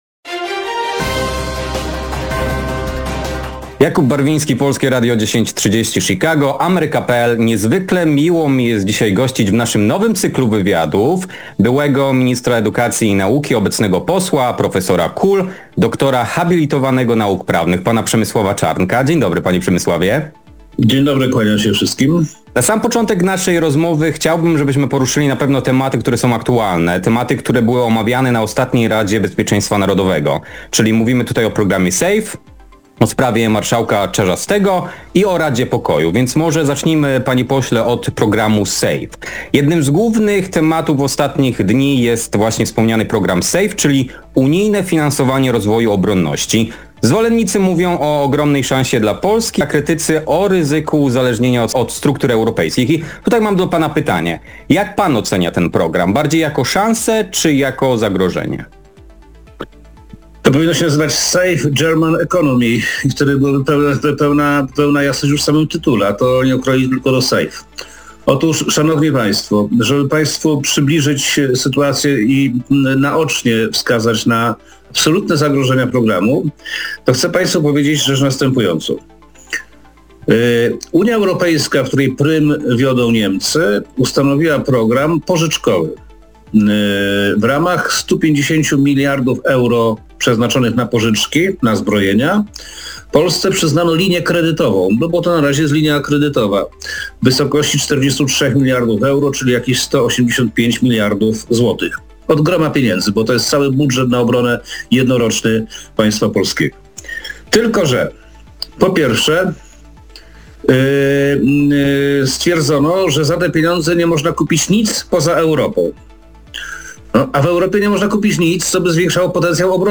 Nowy cykl wywiadów dla AmerykaPL i Polskiego Radia 1030 Chicago. W pierwszym odcinku rozmawiamy z posłem Przemysławem Czarnkiem o sprawach bieżących dotyczących programu Safe, Włodzimierza Czarzastego i kwestii polonijnych.